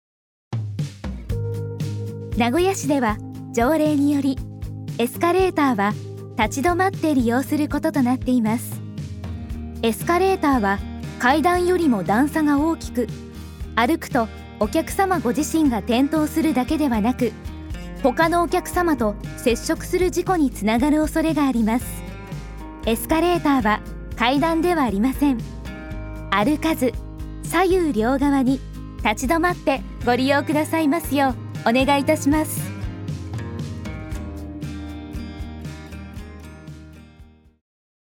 エスカレーター設置施設向け音声案内
エスカレーター設置施設向けの音声案内データです。